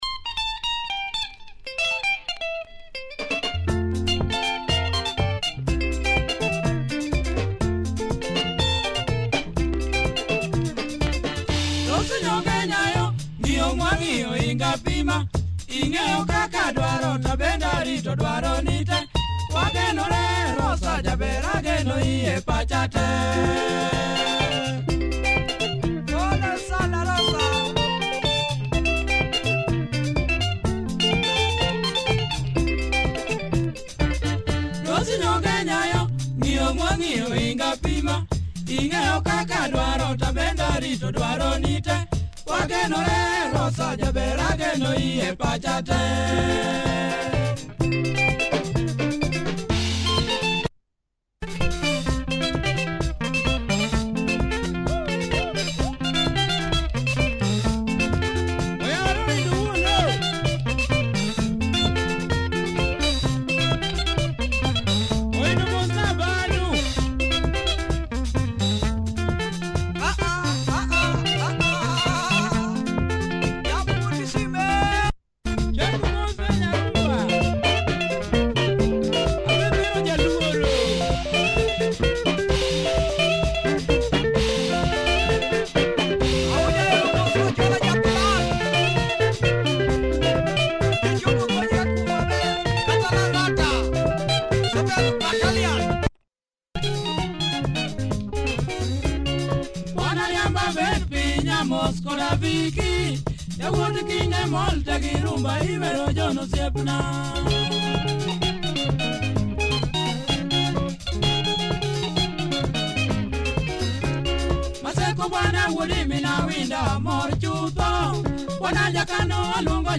Quality LUO benga, steps up the tempo mid-way.